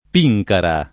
[ p &j kara ]